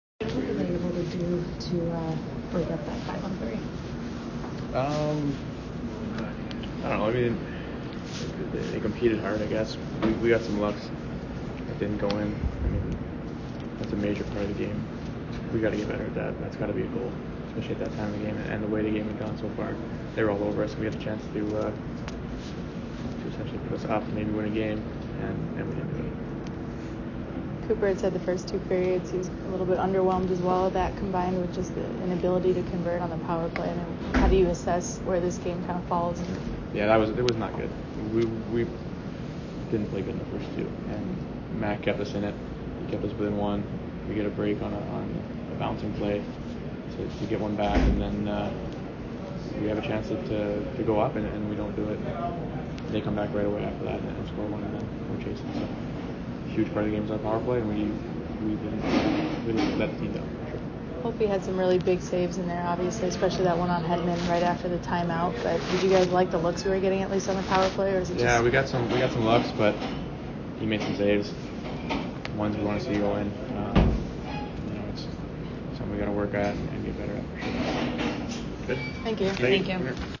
Brayden Point Post - Game At Washington Dec. 21, 2019